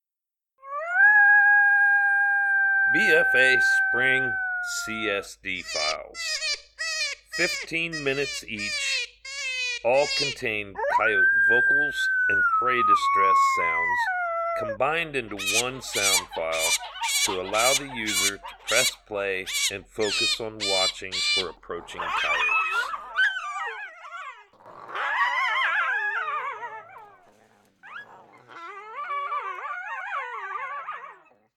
Each BFA Spring CSD File is made up of our most popular Coyote Howls, Coyote Social Vocalizations, Coyote fights and Prey Distress Files.